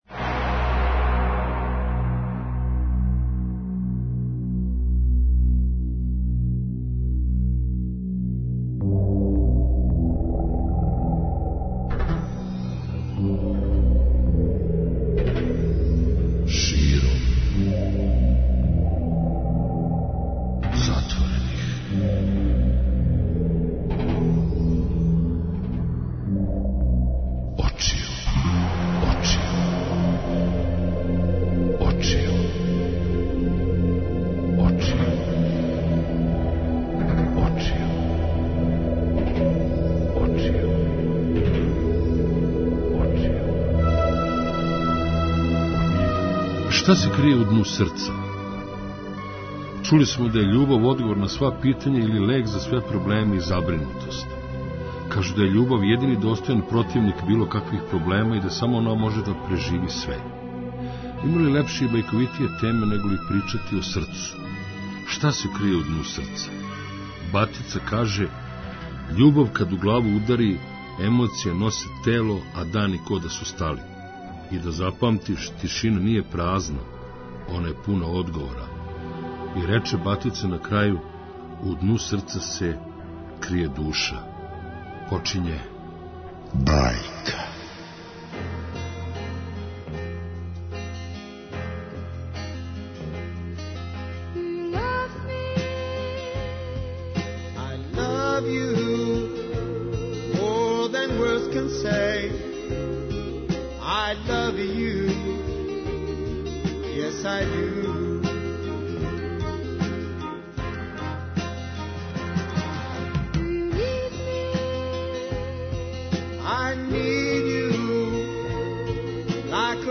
преузми : 42.66 MB Широм затворених очију Autor: Београд 202 Ноћни програм Београда 202 [ детаљније ] Све епизоде серијала Београд 202 Специјал - најбоље за крај Слушај 'вамо!